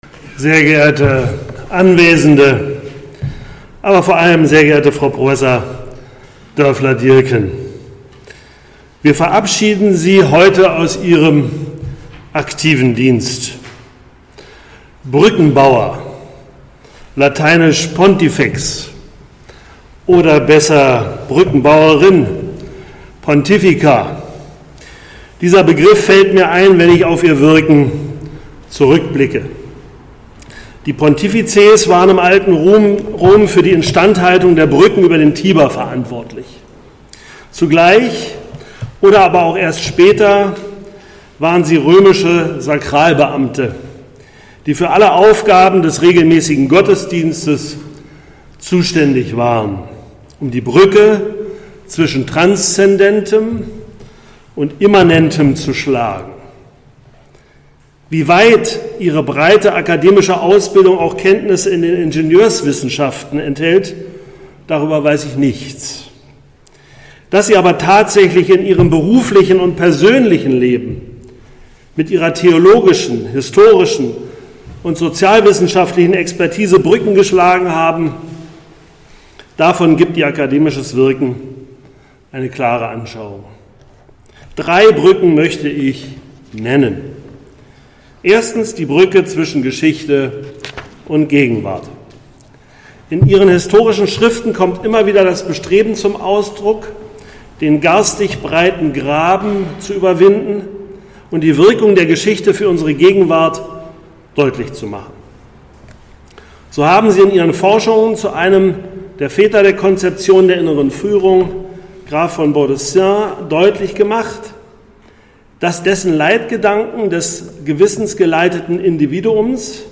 Abschied vom Team ZMSBwZentrum für Militärgeschichte und Sozialwissenschaften der Bundeswehr
Würdigung